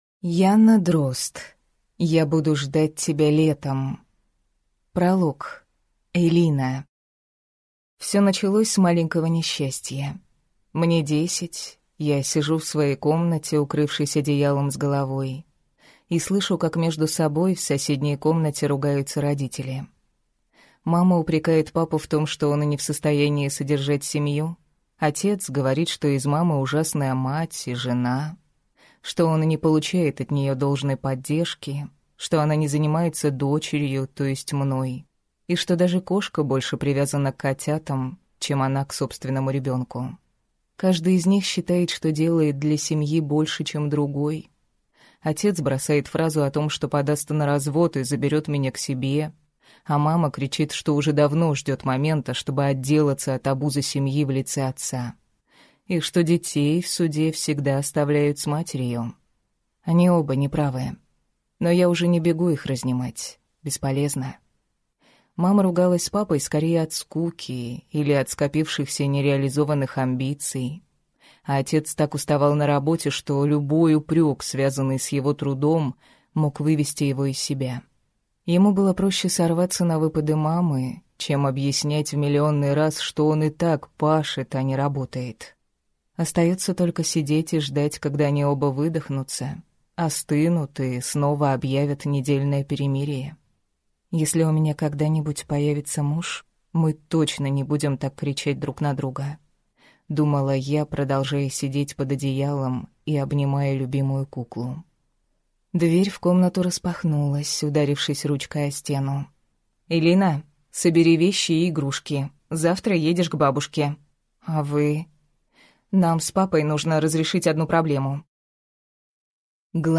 Аудиокнига Я буду ждать тебя летом…